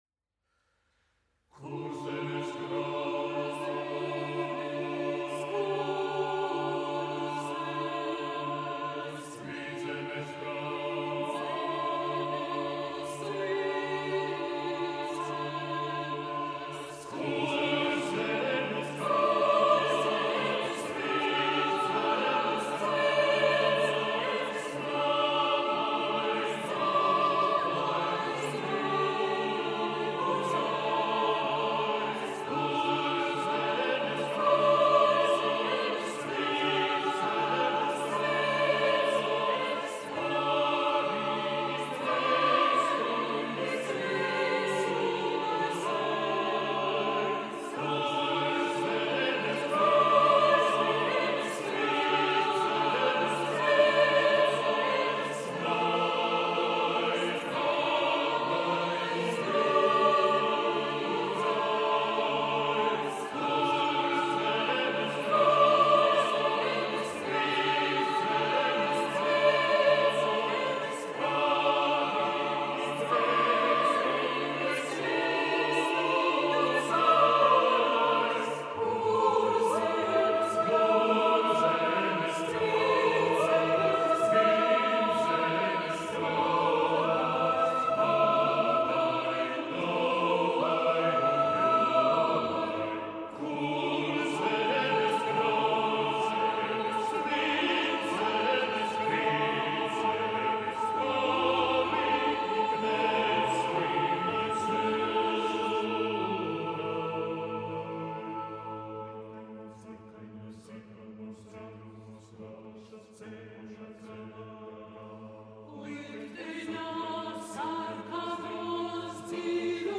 Žanrs: Kormūzika
Instrumentācija: jauktajam korim